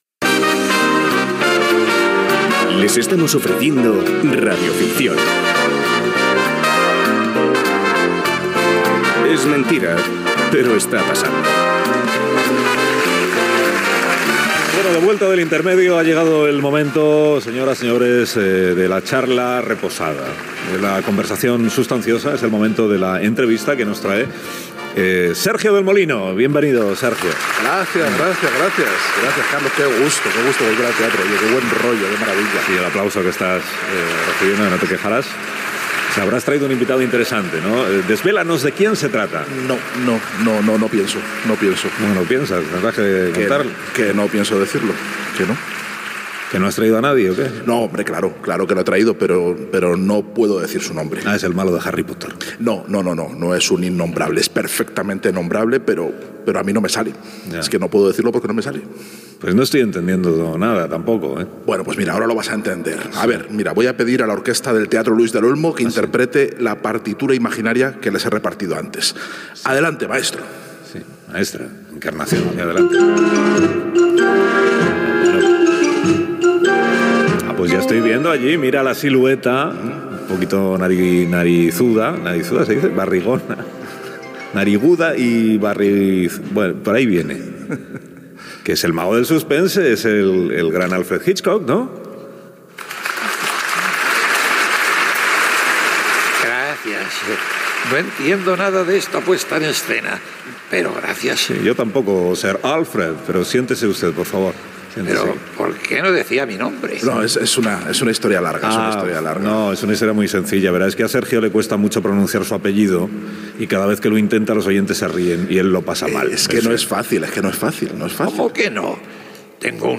"Radioficción", amb una entrevista al director de cinema Alfred Hitchcock des del Teatro Luis del Olmo
Info-entreteniment